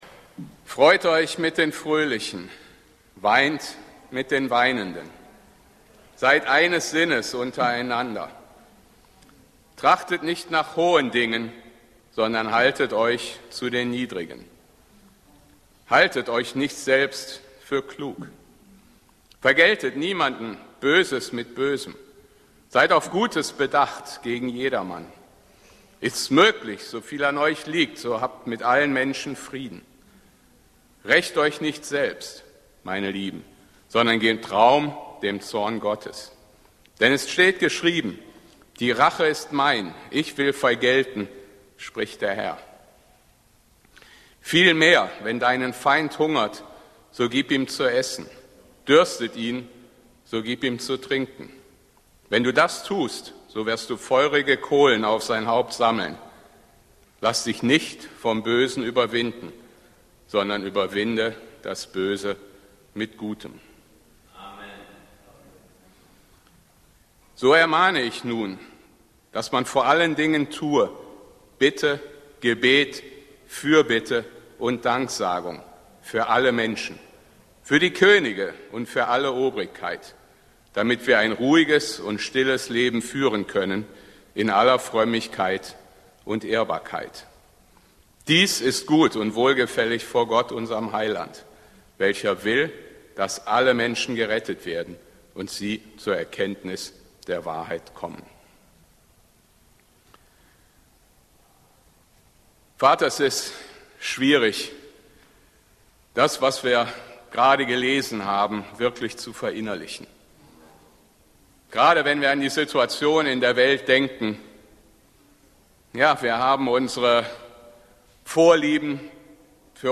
E-Mail Details Predigtserie: Gebet Datum